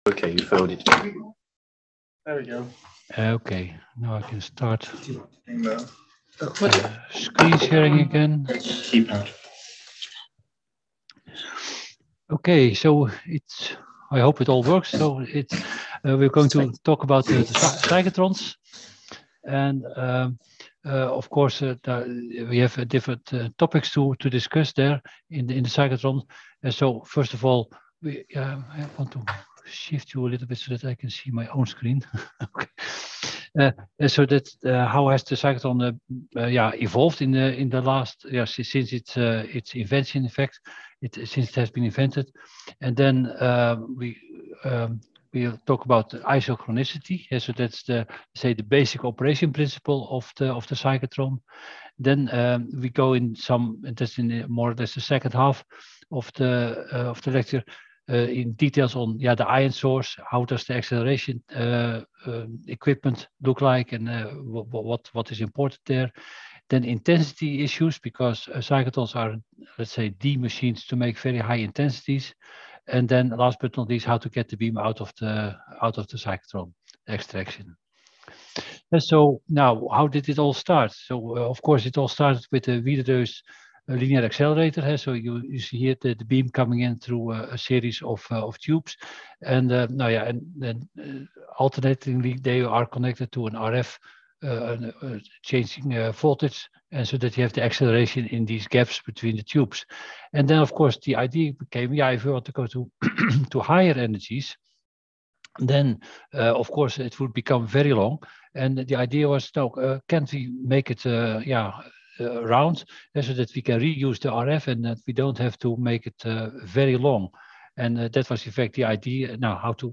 Lecture 7 - Cyclotrons for Various Applications
Videoconference Room, Denys Wilkinson Building, Oxford